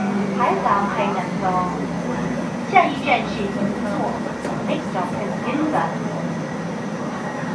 車内の自動音声を聞いていると広東語でナムツォと読んでいるが，
英語音声はギンザ。